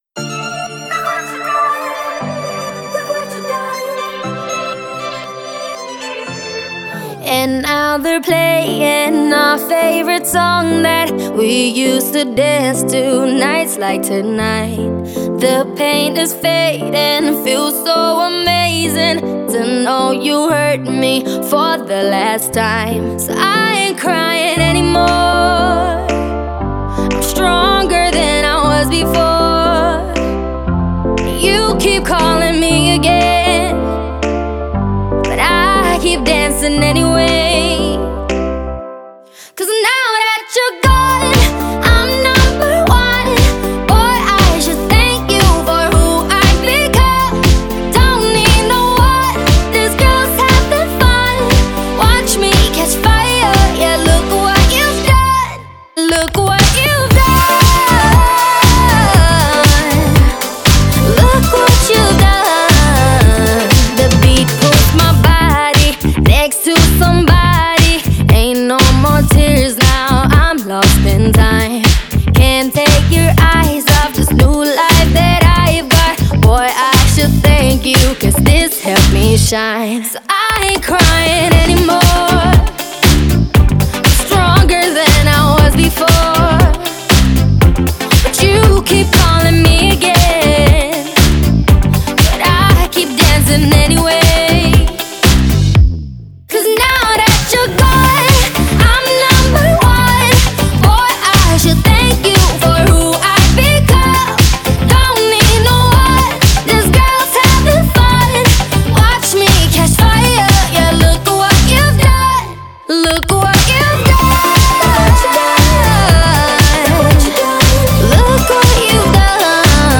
поп-песня